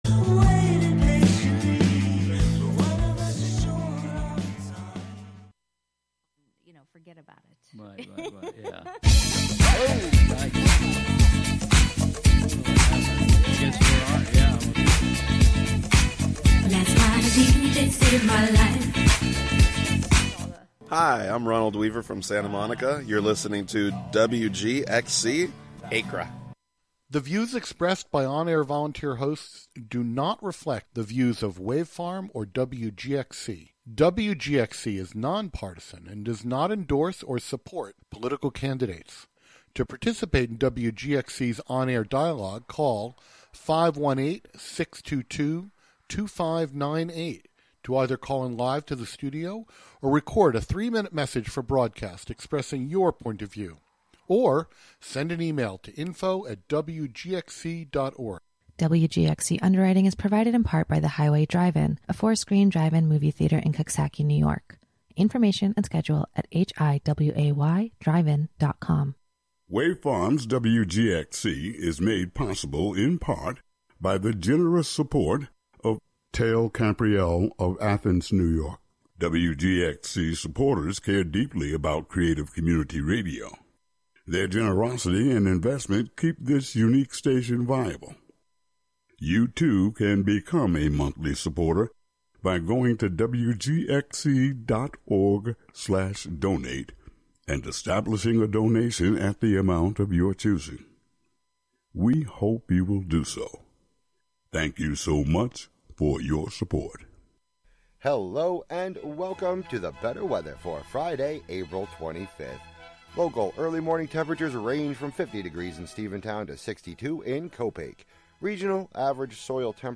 Settle in with a cup of coffee, tea, or bourbon and join us in the conversation.